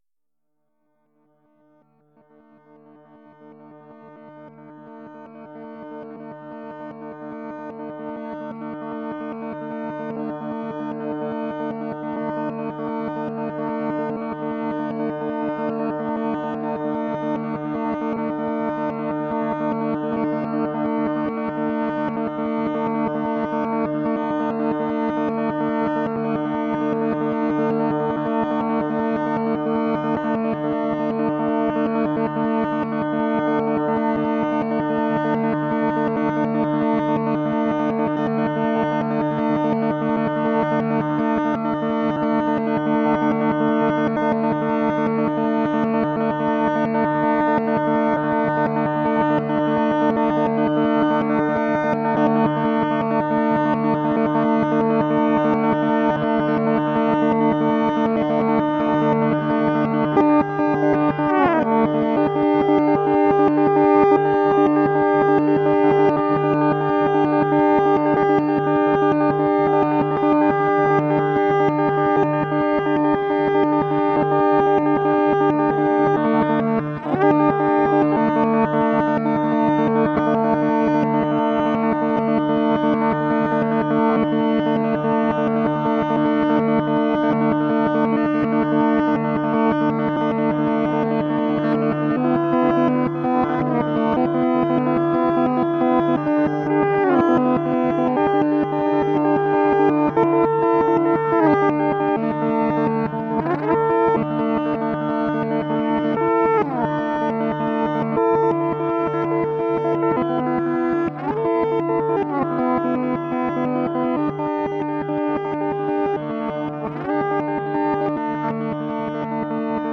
Introspective, melodic and experimental